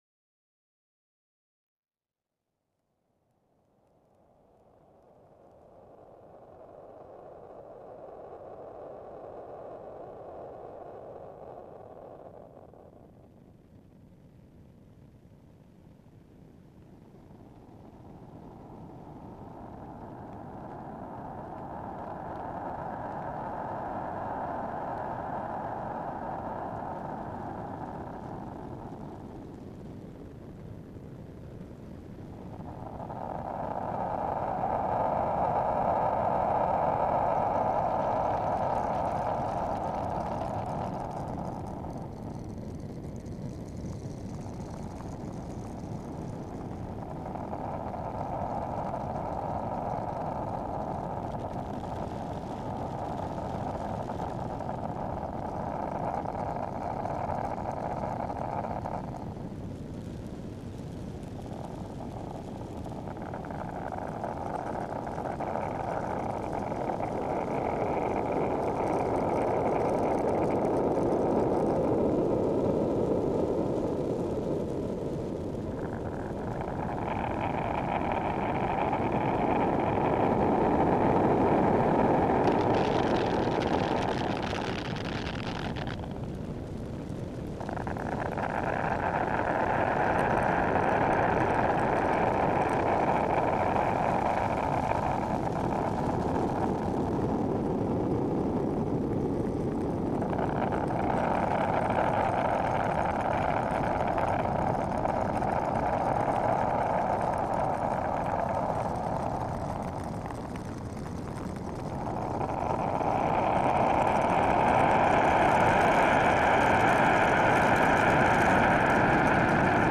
The drone like character remains his important thing.